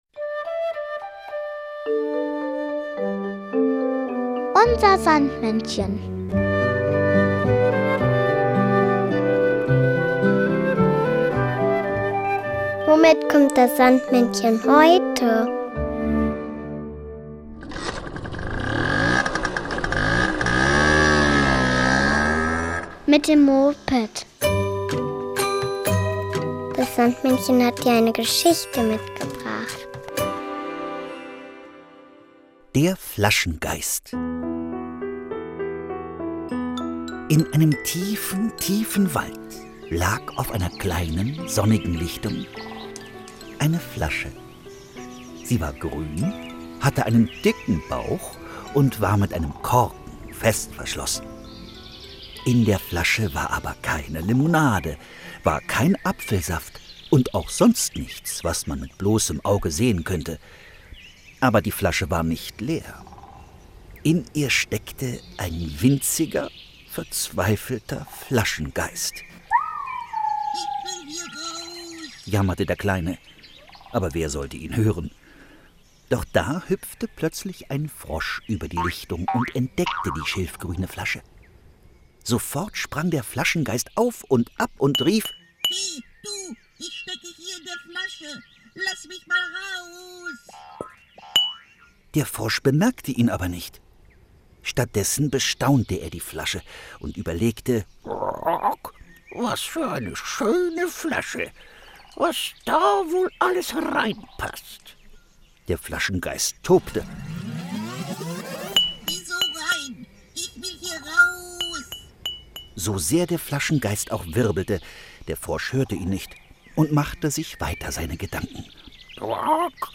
Märchen: Der Flaschengeist